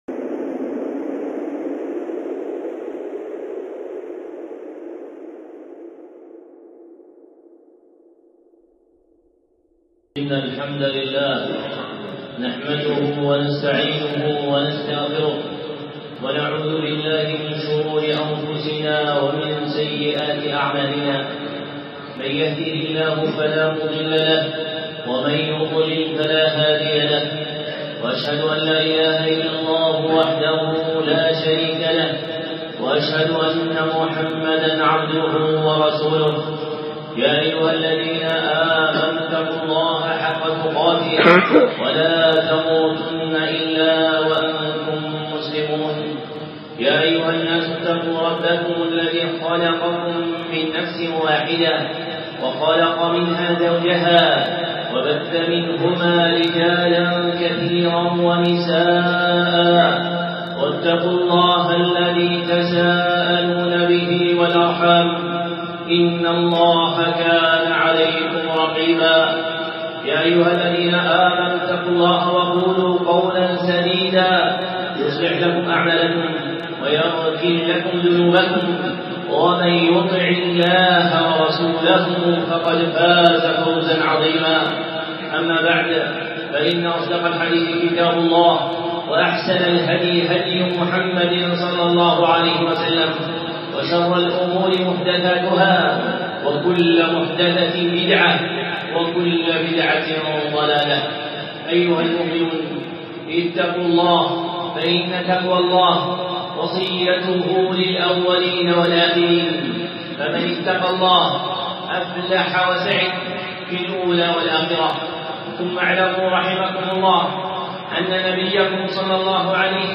خطبة
الخطب المنبرية